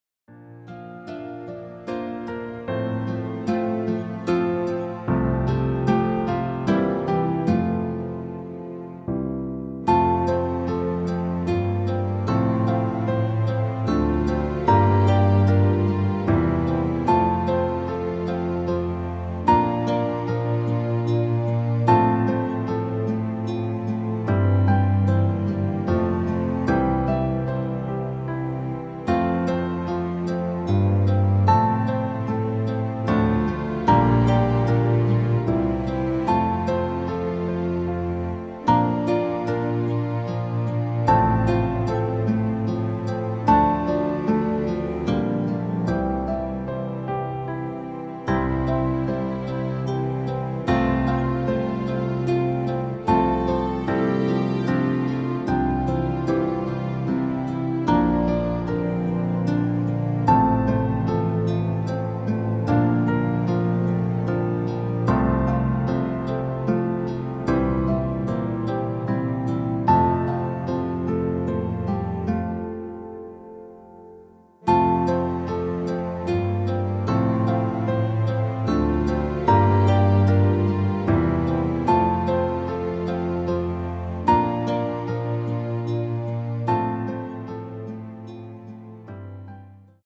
Klavierversion
• Tonart: F Dur , Eb Dur, C Dur
• Art: Klavier Streicher Version
Klavier / Streicher